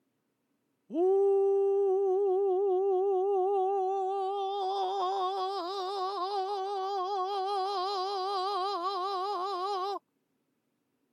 音量注意！
これもよく喉頭を上げて発声しようとする方が陥りがちな声道・共鳴腔全体を強く狭窄させながら発声しているいわゆる詰まった声です。